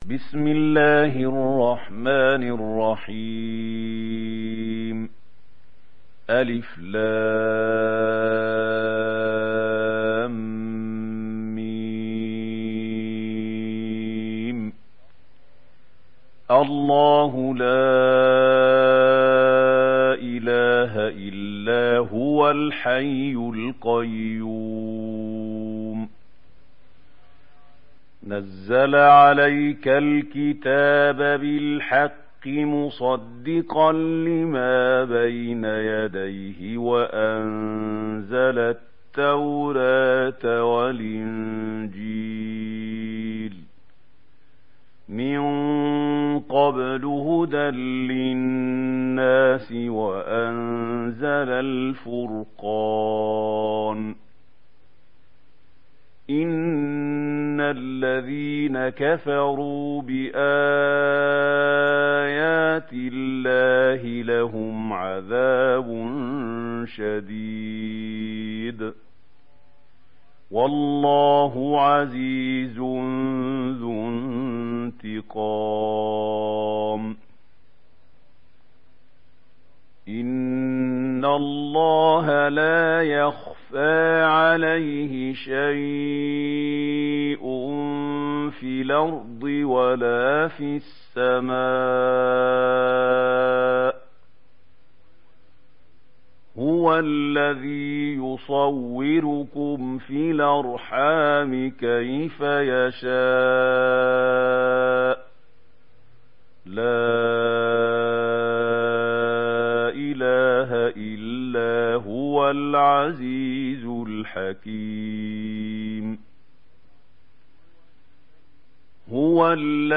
Surat Al Imran Download mp3 Mahmoud Khalil Al Hussary Riwayat Warsh dari Nafi, Download Quran dan mendengarkan mp3 tautan langsung penuh